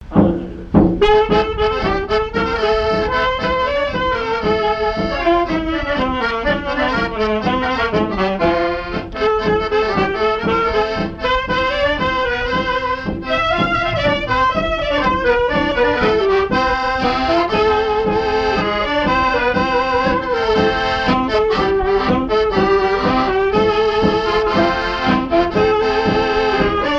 danse : marche
Vie de l'orchestre et son répertoire, danses des années 1950